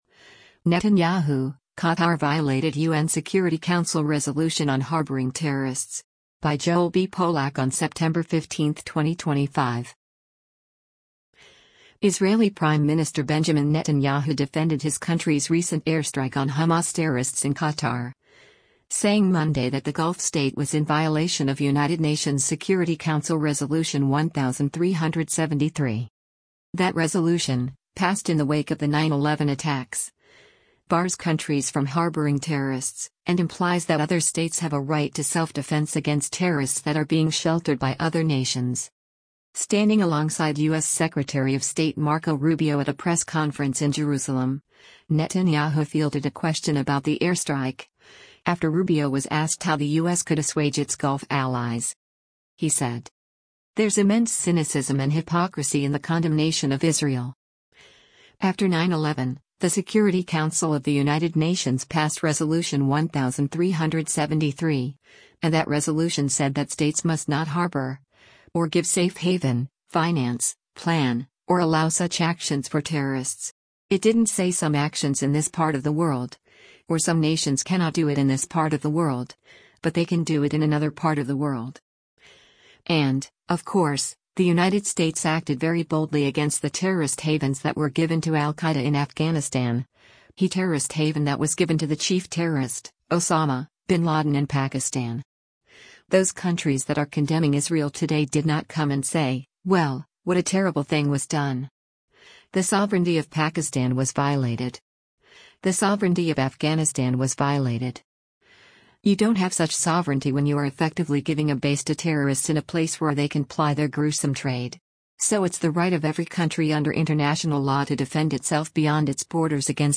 Standing alongside U.S. Secretary of State Marco Rubio at a press conference in Jerusalem, Netanyahu fielded a question about the airstrike, after Rubio was asked how the U.S. could assuage its Gulf allies.